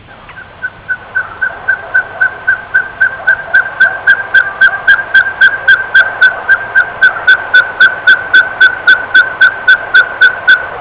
El Chuncho (Glaucidium nanum)